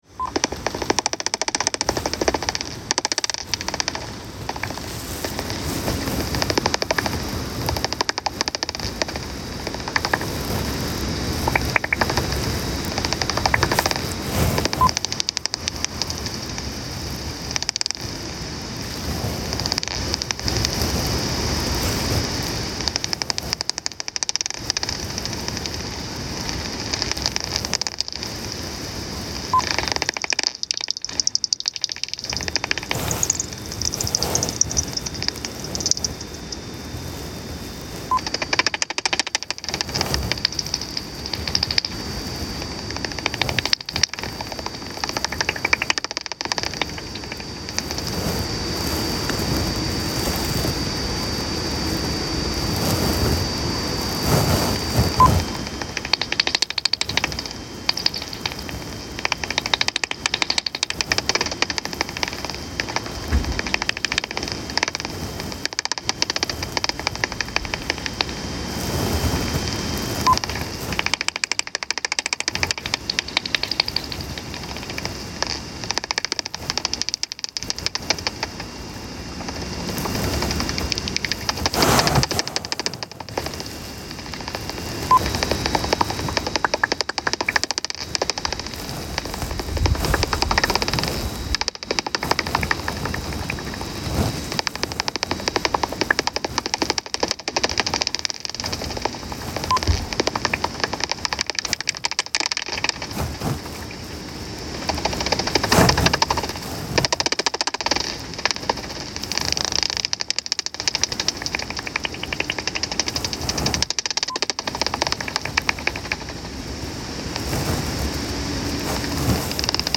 The beep of the bat detector
The sounds of a bat detector converting bat echolocation calls into audible frequencies on a bat behaviour/nesting site survey in Oxfordshire.